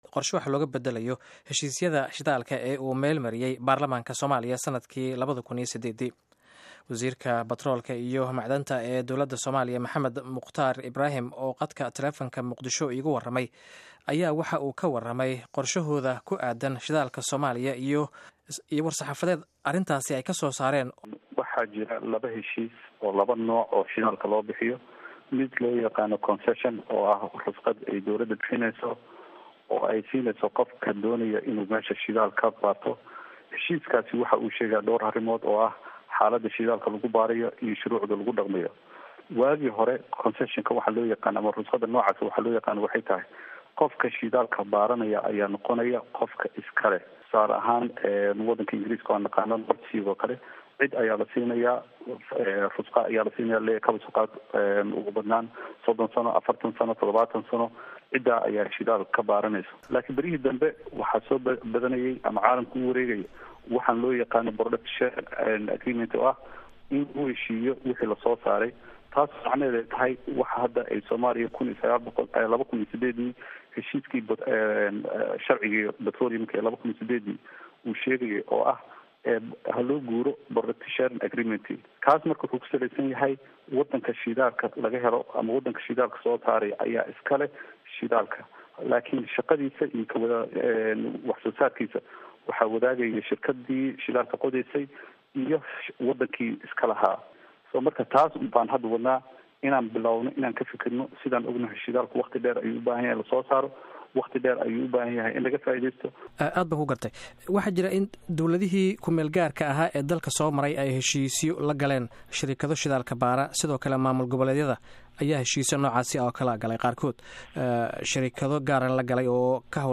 Maxamed Mukhtar Wasiirka Batroolka iyo Macdanta oo idaacada VOA waraysatay ayaa u sheegay inay dowladda ay aqoonsatahay heshiisyadii horey loola galay shirkaddaha markii dowladdii Somalia ay jirtay, balse wuxuu sheegay in imika wasaaraddiisu ay qorsheynayso in la helo shuruuc waafaqsan federaalka iyo rabitaanka dadka, si shacabka looga faaideeyo kheyraadka Soomaaliya.
Dhagayso hadalka wasiirka DFS